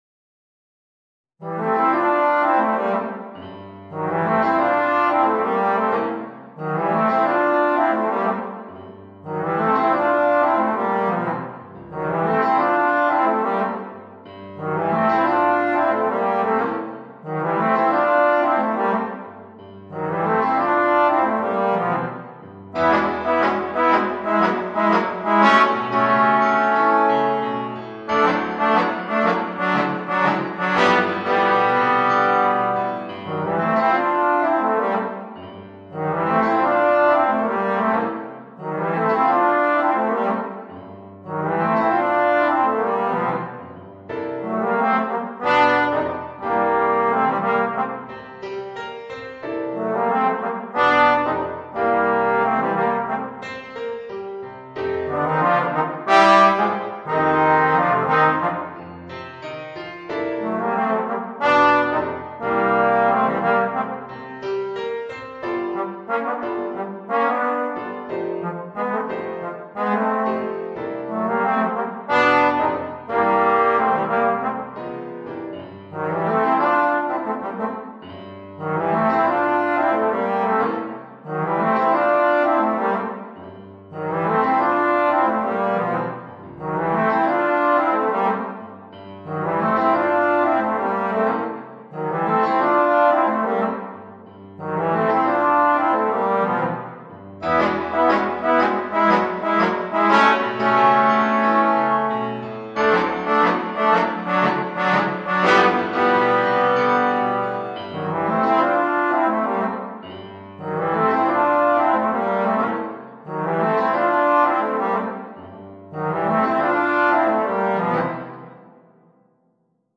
Voicing: 2 Trombones and Piano